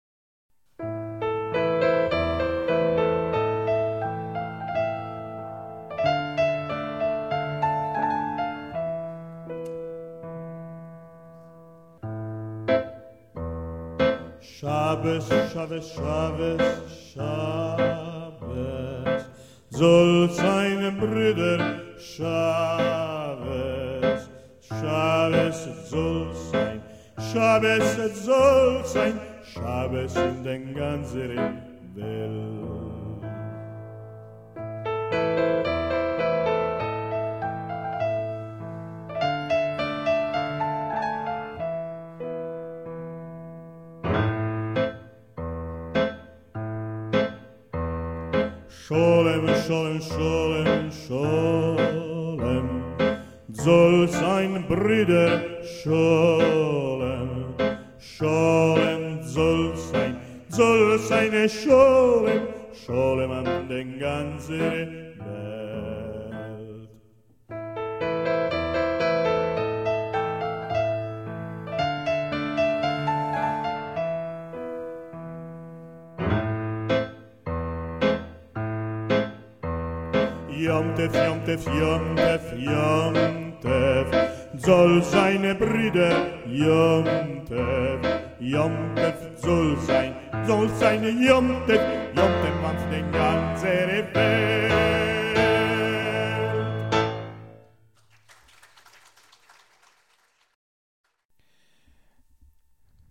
Shabes canto ashkenazita di gioia sabbatica
piano
registrazione effettuata al Teatro Paisiello di Lecce il 27 gennaio 2004